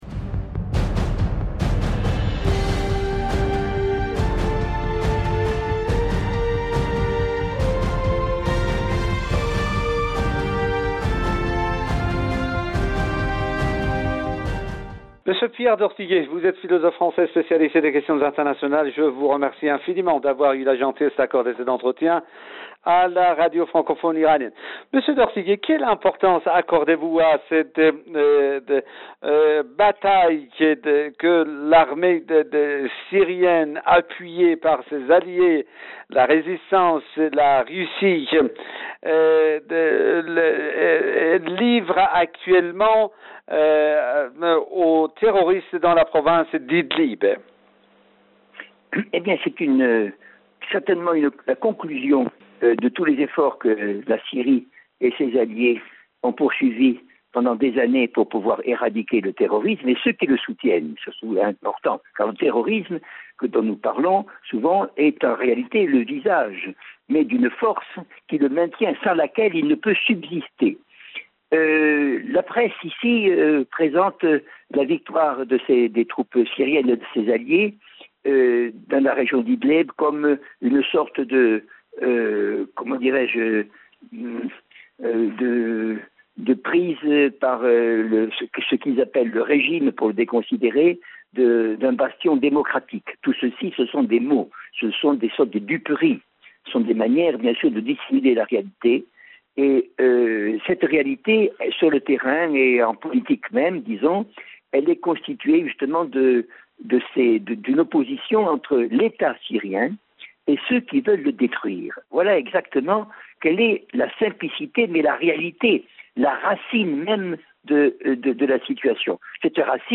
table ronde